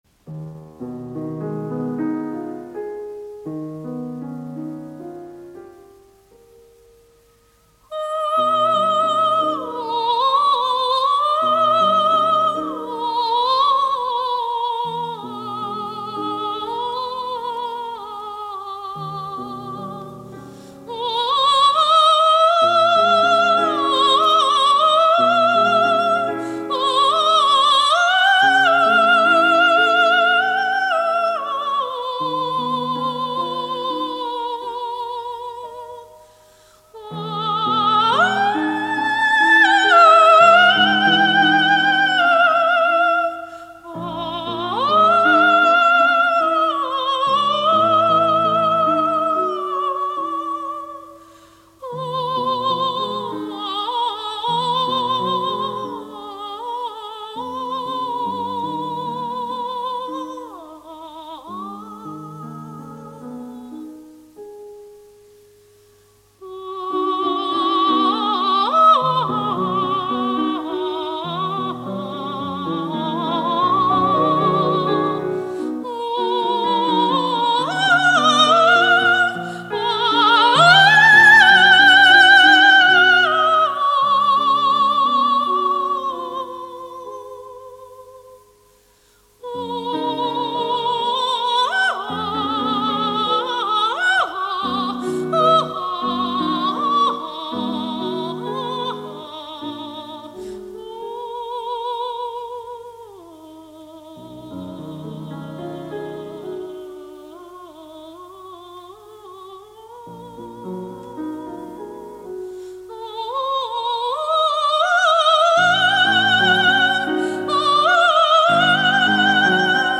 ВОКАЛИЗ – это произведение, написанное для  голоса без слов.